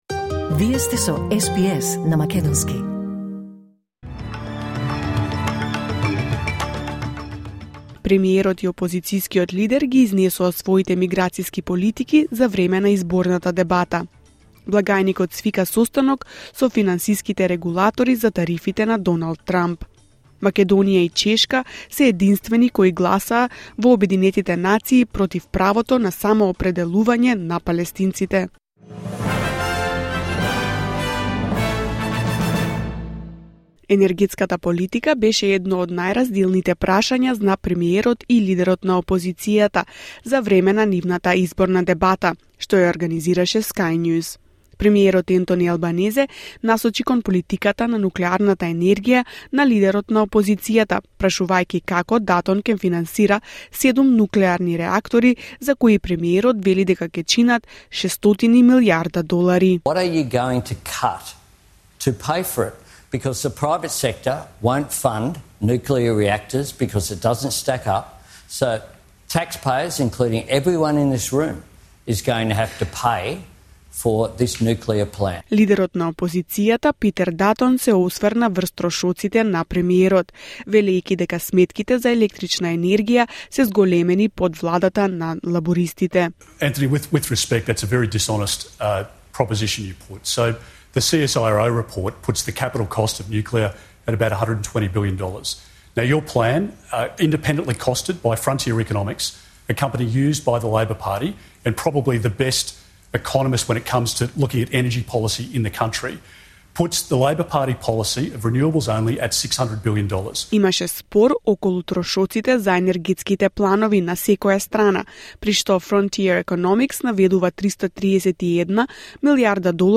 Вести на СБС на македонски 9 април 2025